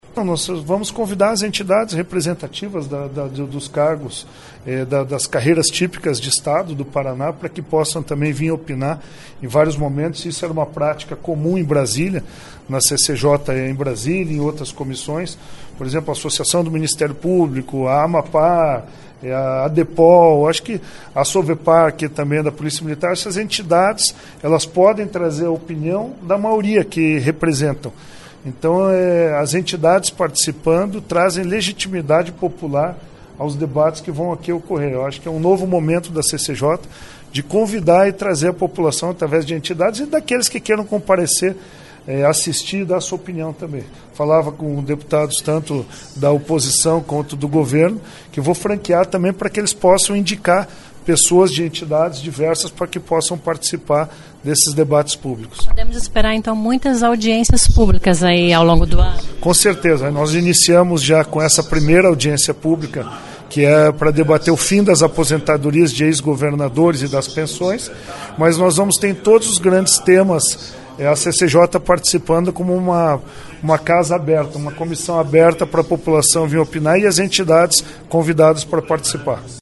A primeira audiência pública acontece já na semana que vem e vai debater a PEC que pretende extinguir aposentadoria de ex-governadores. Ouça a entrevista de Fernando Francischini (PSL), presidente da CCJ.